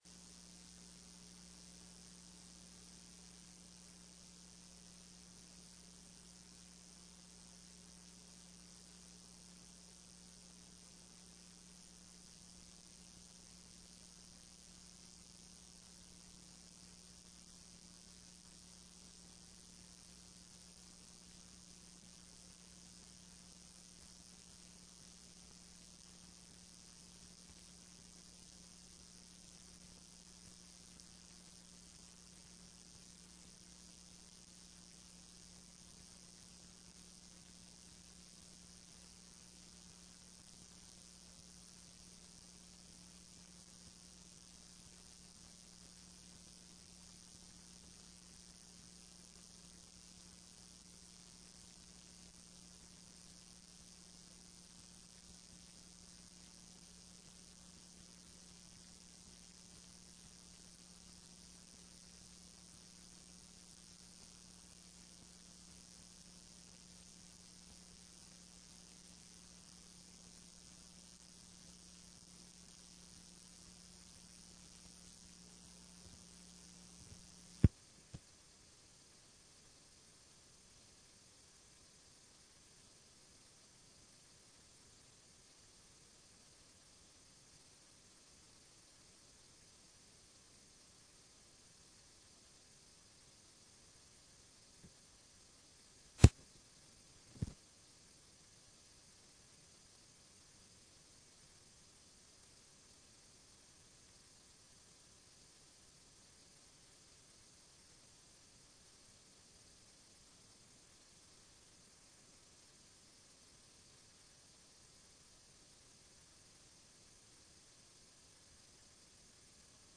Sessão Plenária TRE-ES dia 04/02/15